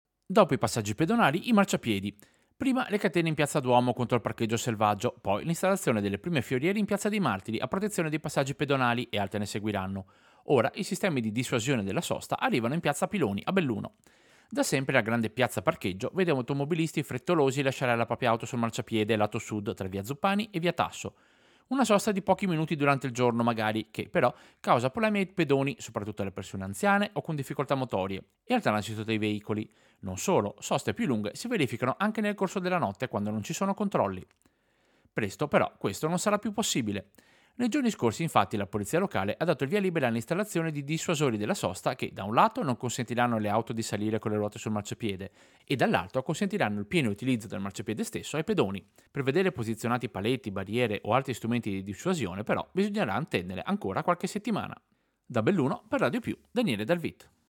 Servizio-Dissuasori-sosta-Piazza-Piloni.mp3